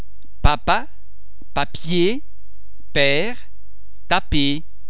The French [p] in contrast to the English [p] is produced with lots of facial muscle and lip tension, so as to create a very explosive [p] without the release of the tension into a more or less audible aspiration.
Listen to the samples and hear the French [p]